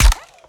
Added more sound effects.
GUNAuto_RPU1 Fire_04_SFRMS_SCIWPNS.wav